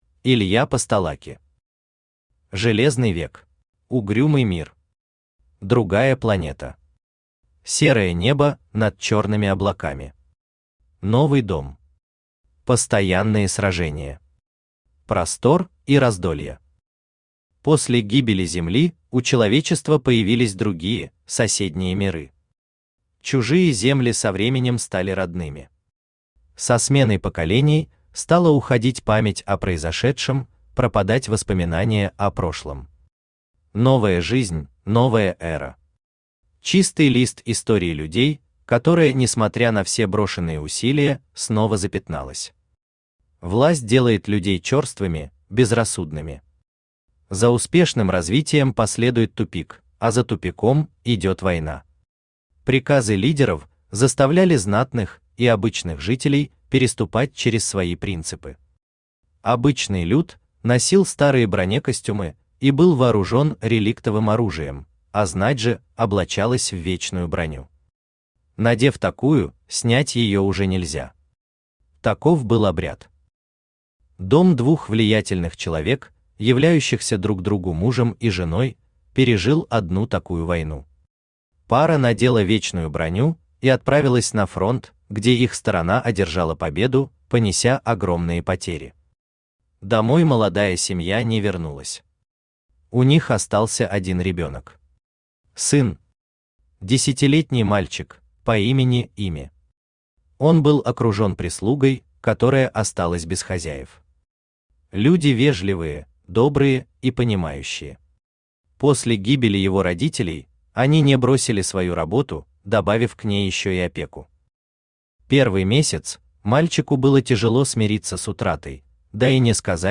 Аудиокнига Железный век | Библиотека аудиокниг
Aудиокнига Железный век Автор Илья Олегович Постолаки Читает аудиокнигу Авточтец ЛитРес.